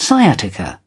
Transcription and pronunciation of the word "sciatica" in British and American variants.